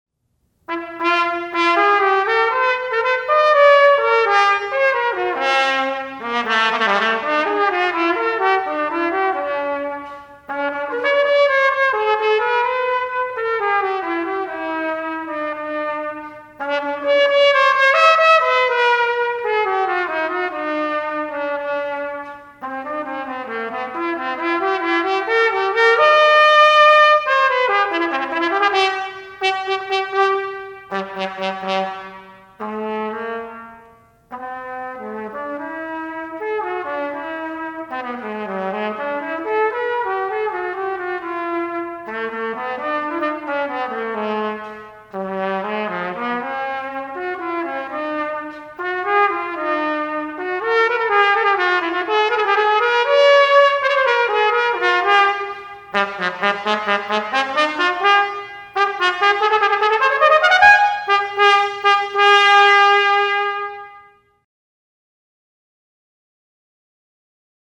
for Bb Trumpet Unaccompanied
With conviction